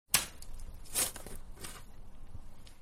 Shovel1.wav